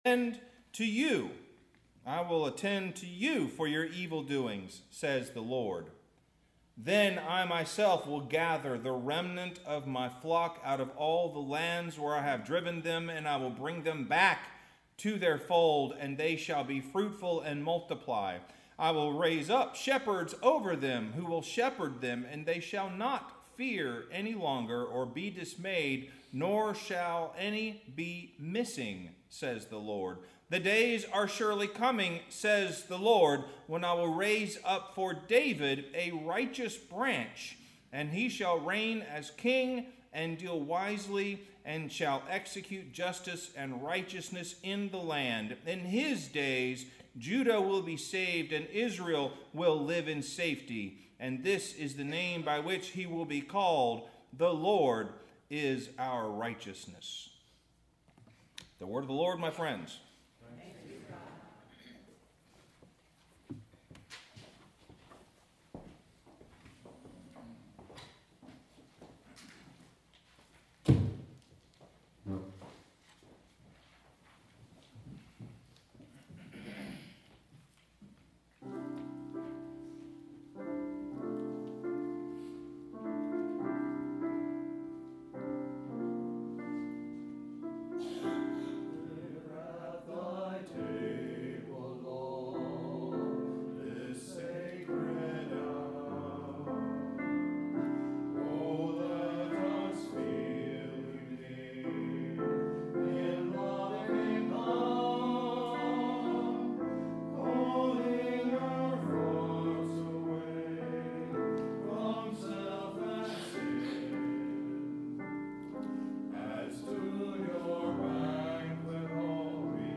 Sermon – Scattered and Gathered